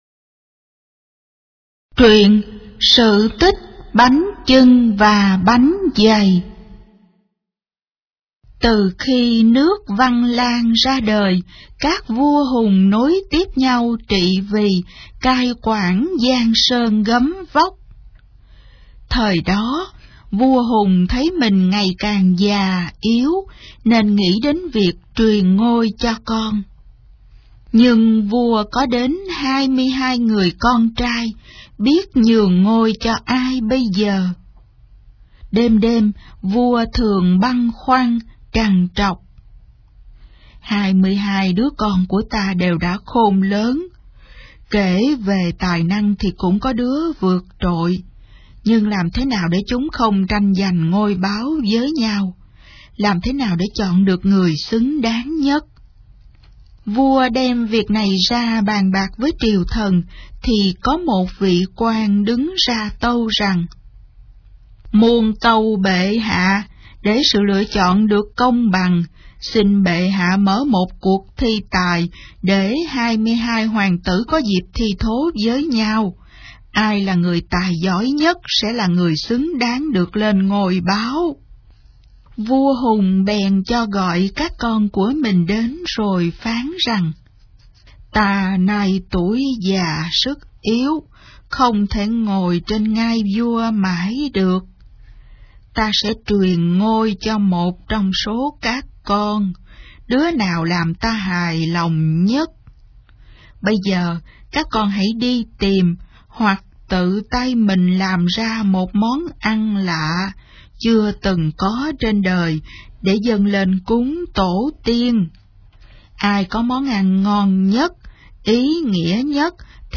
Sách nói | Sự tích Bánh chưng bánh giầy